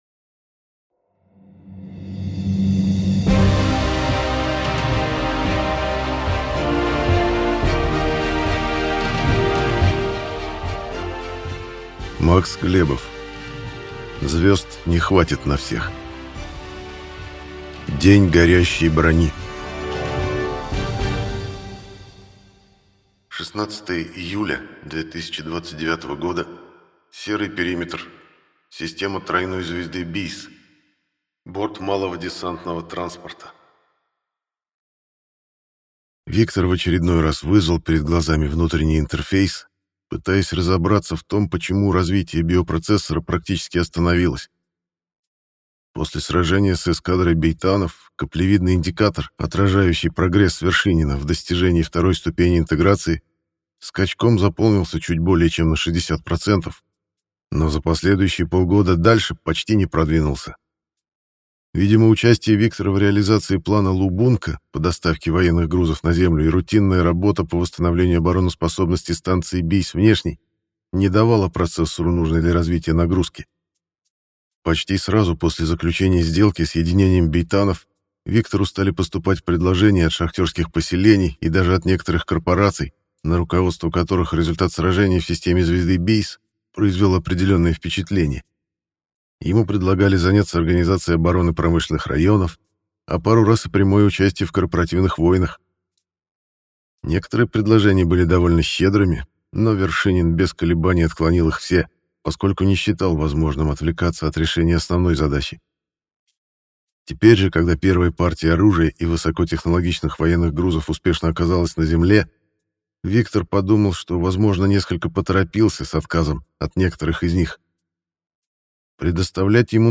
Аудиокнига Звезд не хватит на всех. День горящей брони | Библиотека аудиокниг